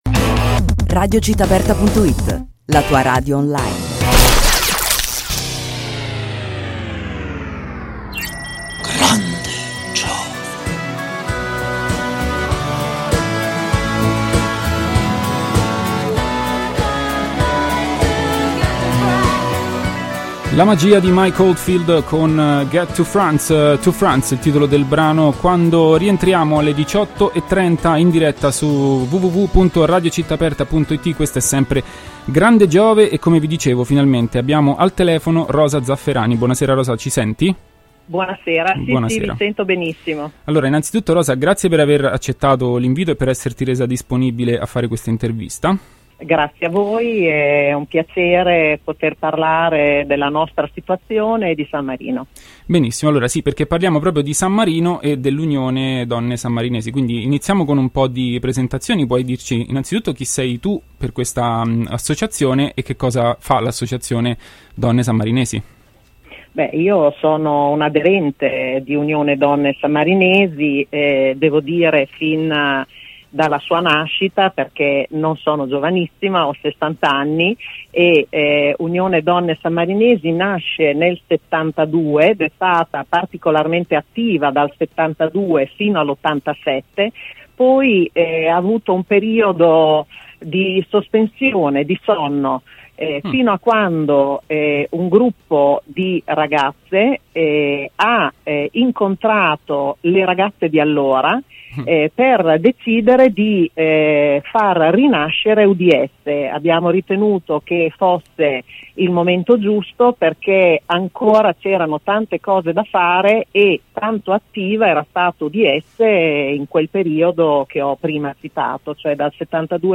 Intervista Unione Donne Sammarinesi